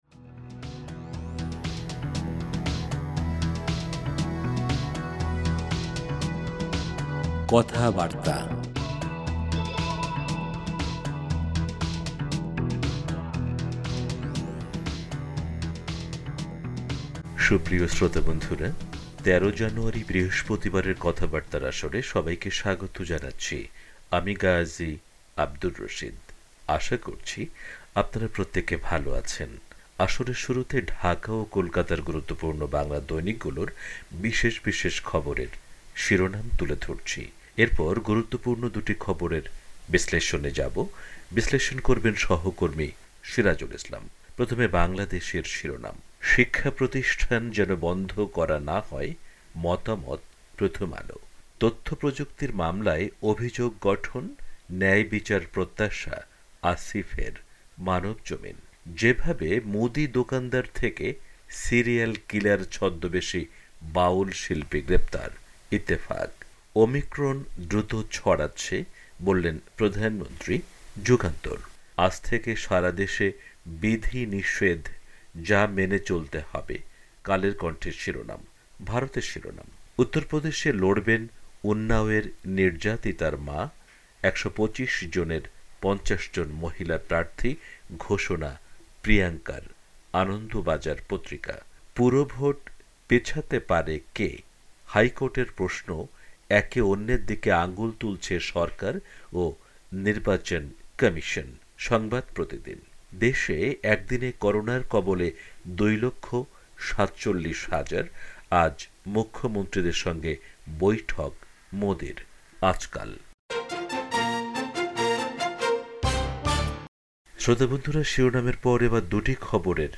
আসরের শুরুতে ঢাকা ও কোলকাতার গুরুত্বপূর্ণ বাংলা দৈনিকগুলোর বিশেষ বিশেষ খবরের শিরোনাম তুলে ধরছি। এরপর গুরুত্বপূর্ণ দুটি খবরের বিশ্লেষণে যাবো।